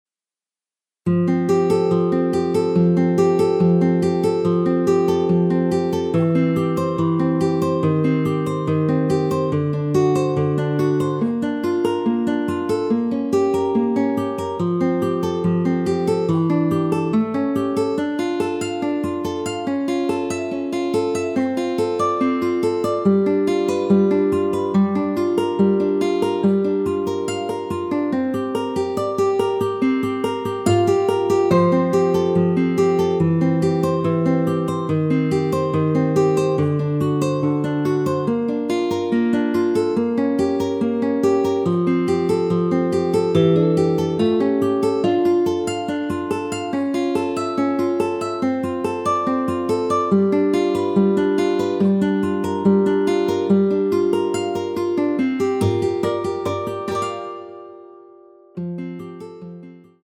여성분이 부르실수 있는 키 입니다.(미리듣기 참조)
앞부분30초, 뒷부분30초씩 편집해서 올려 드리고 있습니다.
중간에 음이 끈어지고 다시 나오는 이유는
곡명 옆 (-1)은 반음 내림, (+1)은 반음 올림 입니다.